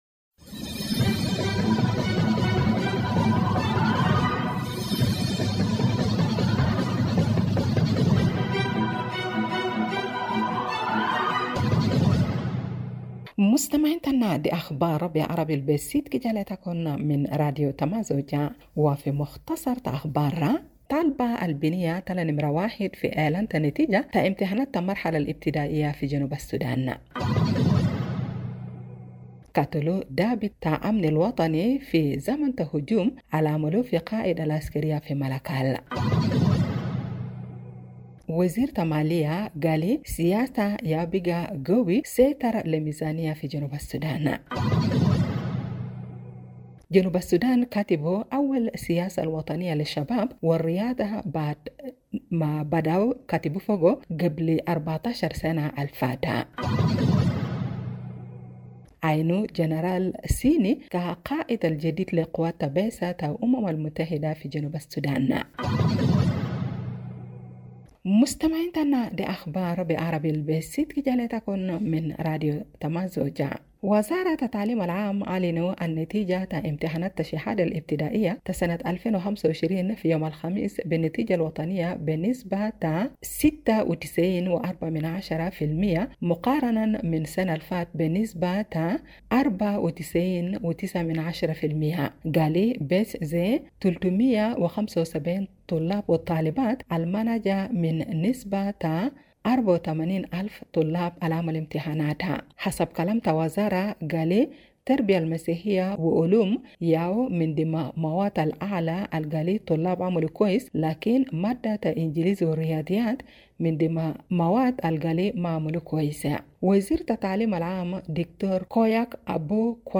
Evening Broadcast 06 March Juba Arabic News